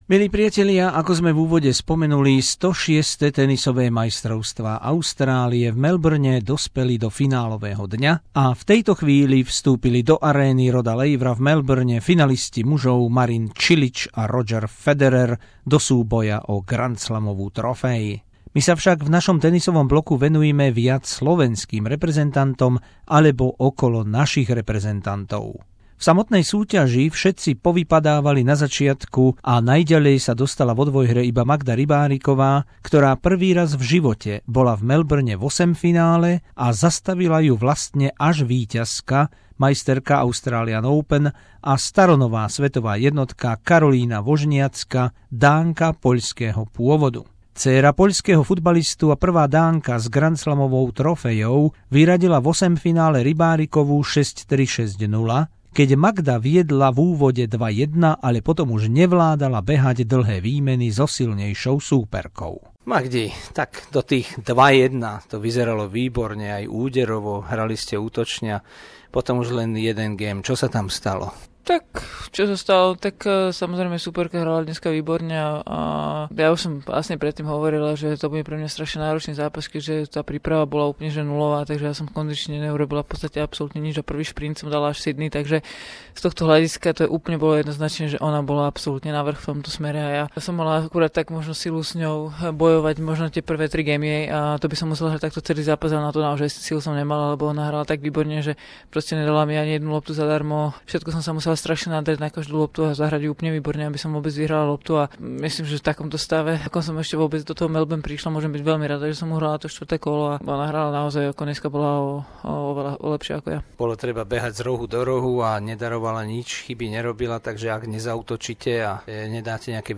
Rozhovory